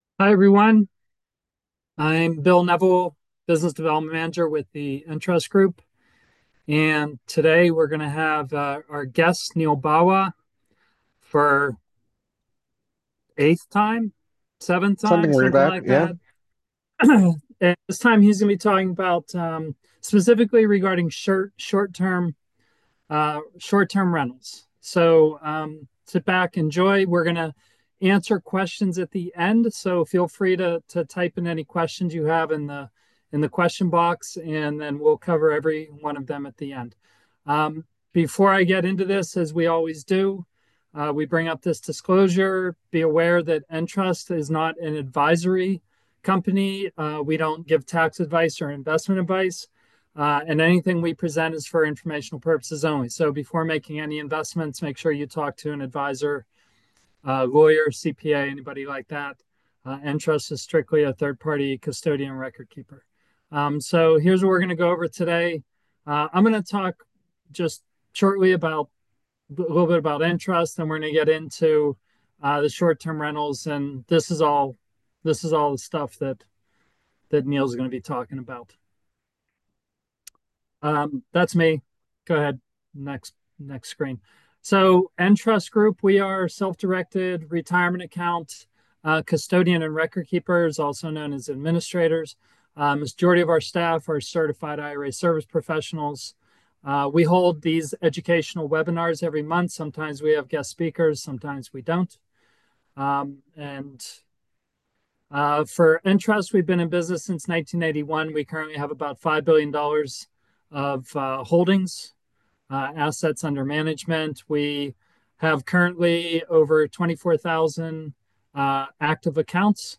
Webinar_November_2024_Audio_Replay.m4a